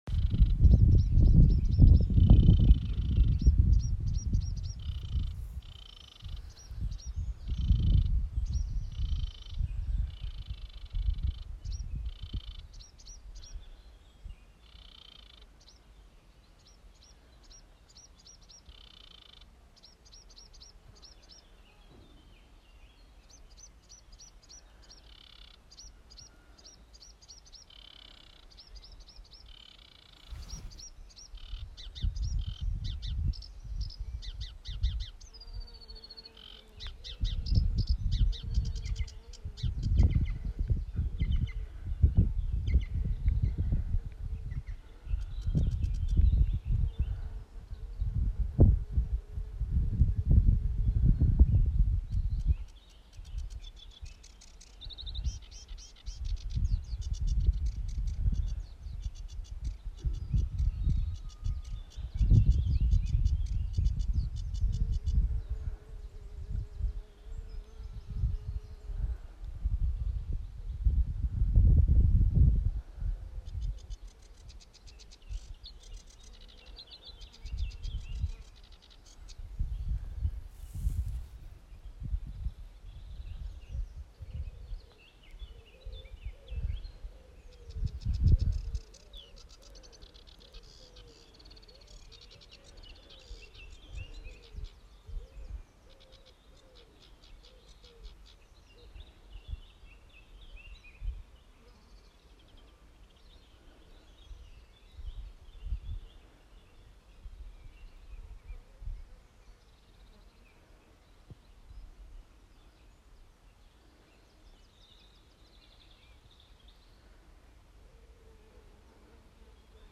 A single sedge warbler, a small migrant bird, with its rasping along with occasional bee, woodpecker